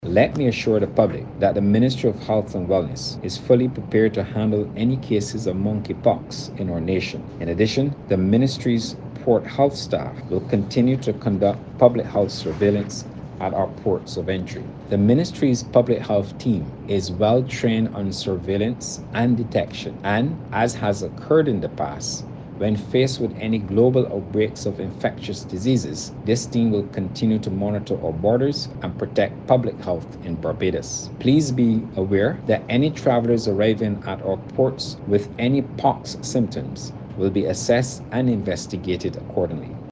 Voice of: Minister of Health and Wellness, Ian Gooding-Edghill